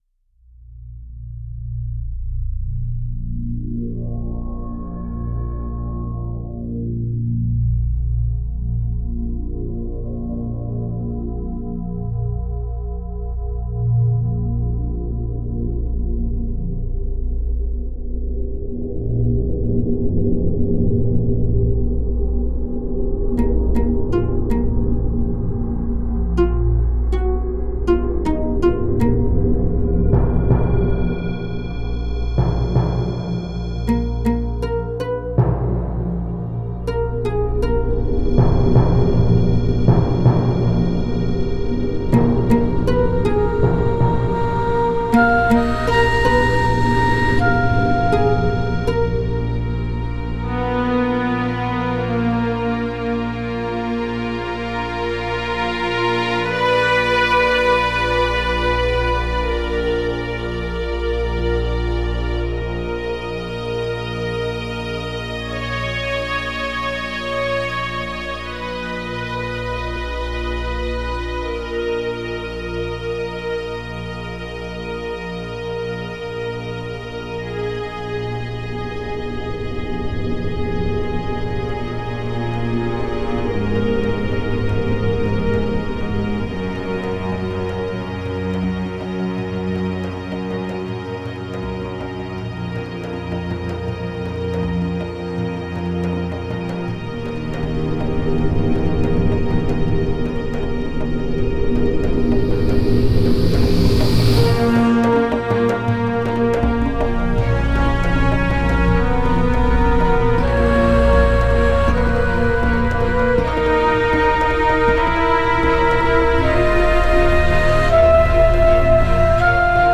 Téléchargez la composition musicale (MP3) ou écoutez-la en ligne directement